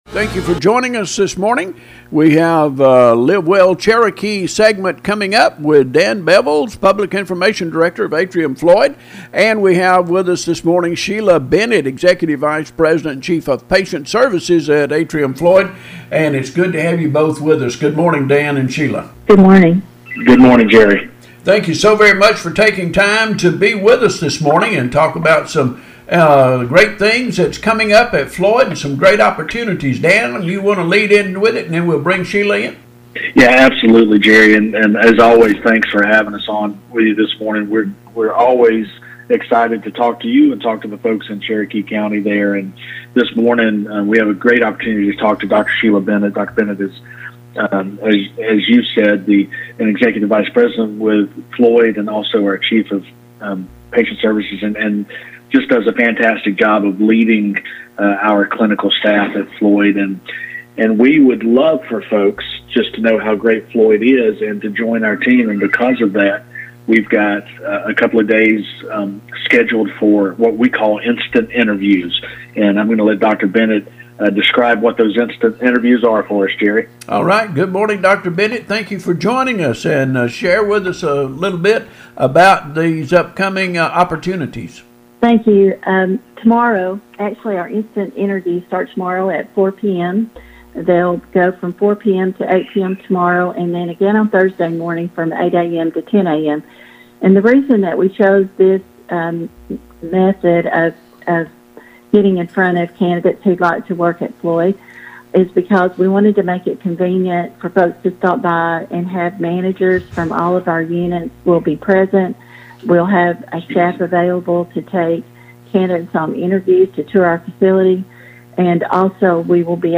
To hear that interview in its entirety simply click the link below / THIS INTERVIEW WAS CONDUCTED TUESDAY – the “Instant Interviews” are taking place on Wednesday and Thursday